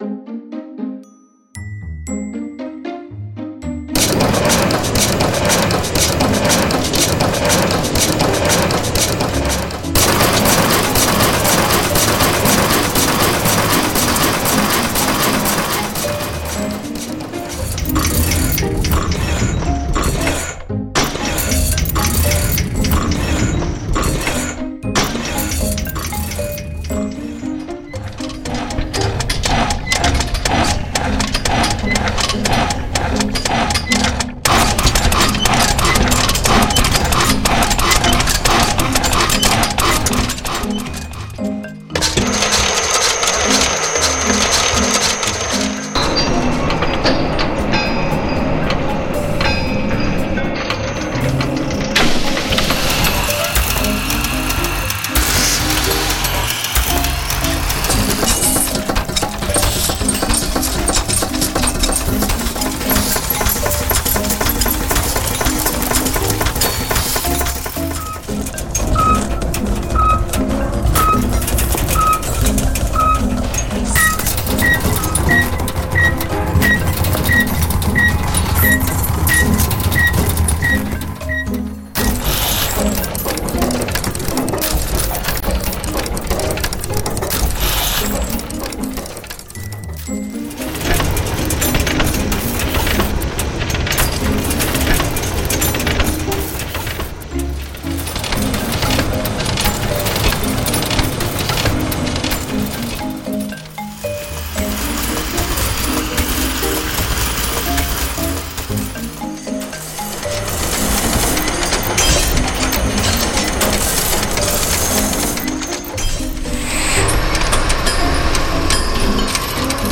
音效素材-神奇的蒸汽机械运转工作无损声音特效73种
该素材库中的所有音效均以高质量标准录制和制作，确保了出色的声音品质，能够满足专业级项目的要求。